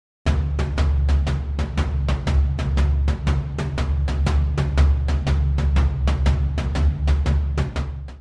9. Мелодия римичная